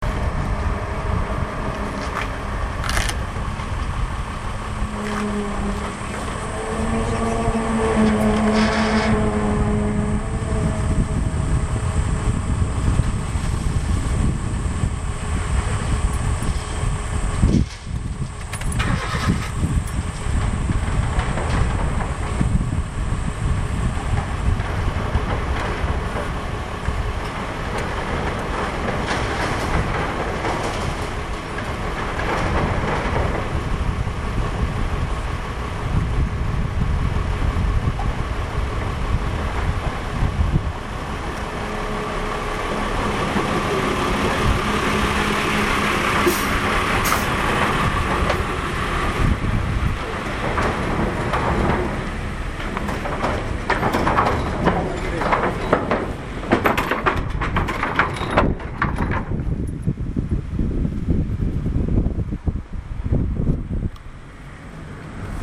Daintree Ferry Soundscape
ferry-soundscape.mp3